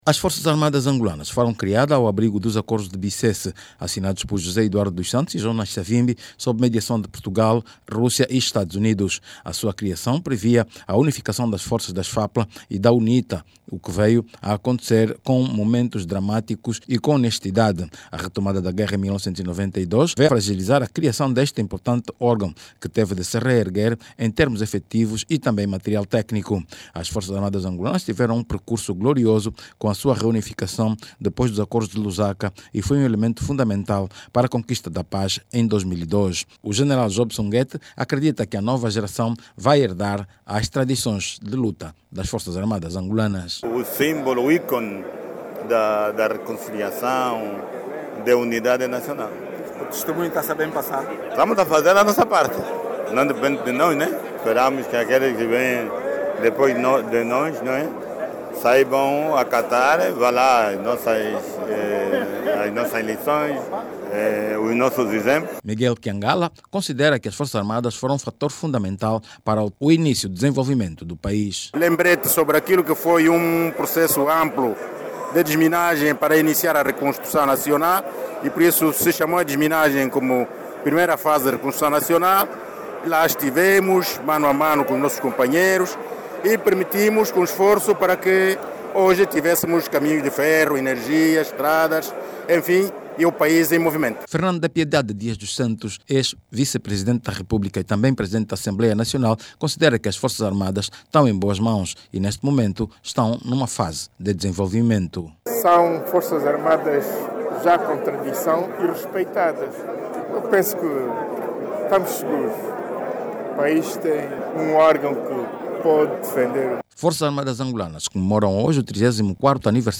As Forças Armadas Angolanas está a comemorar hoje, quinta-feira, 09 de Outubro, 34 anos de existência como um verdadeiro exemplo de unidade nacional e factor preponderante para a estabilidade do país. Nesta altura, o órgão está a viver um processo de restruturação e modernização. Saiba mais dados no áudio abaixo com o repórter